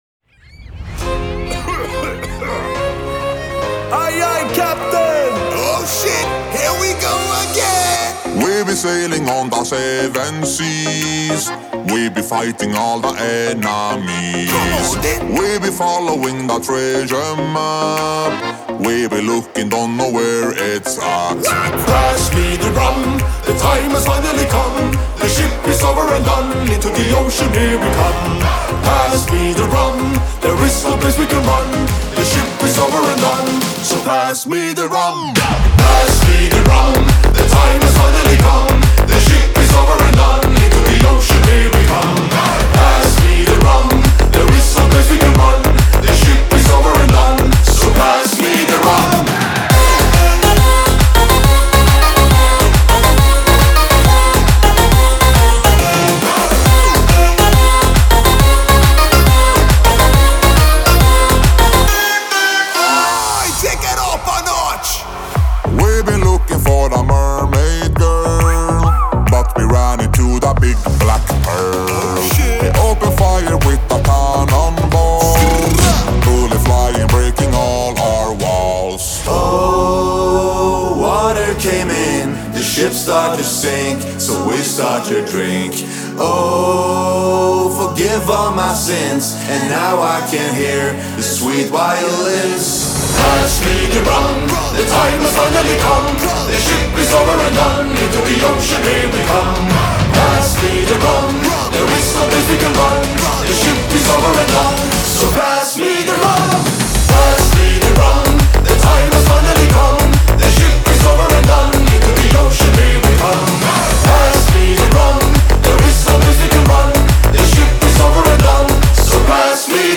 Клубная